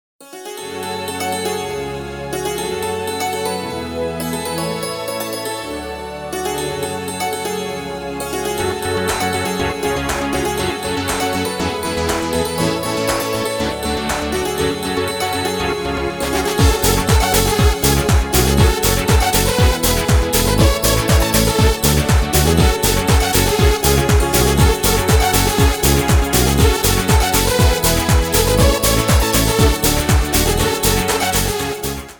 Звонкая мелодия на рингтон